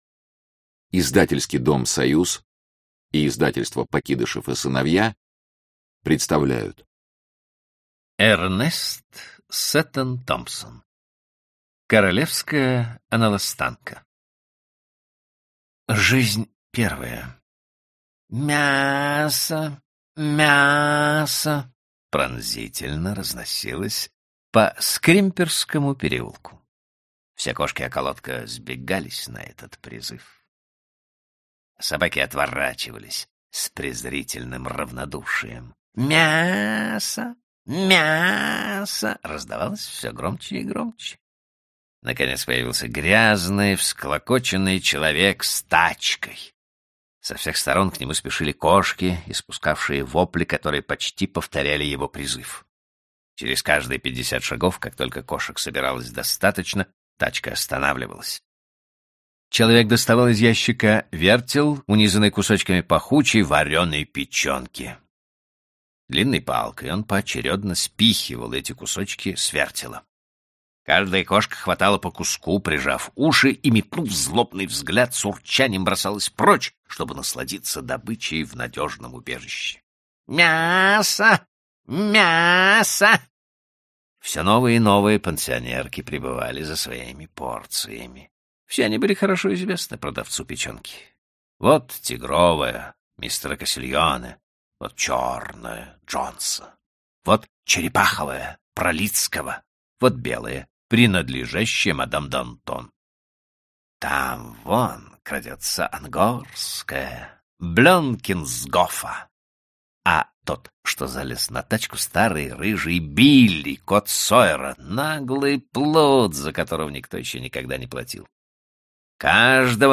Аудиокнига Рассказы о животных | Библиотека аудиокниг